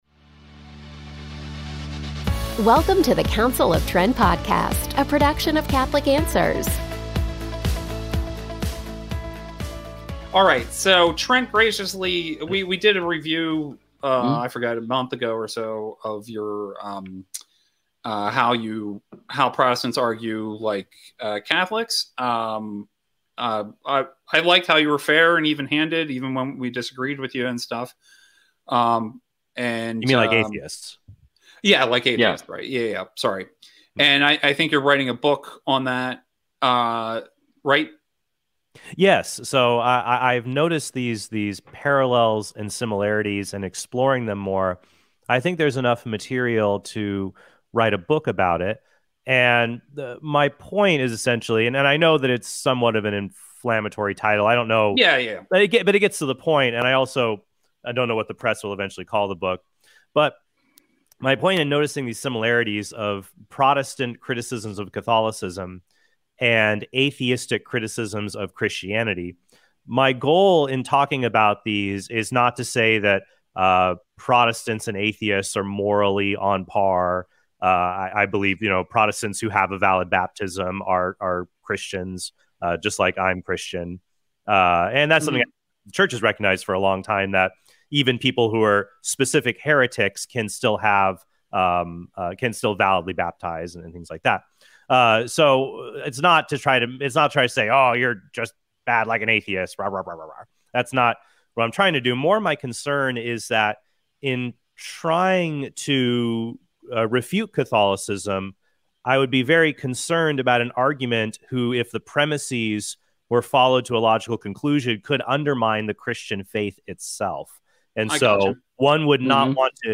DIALOGUE: Do Protestants Act like Atheists?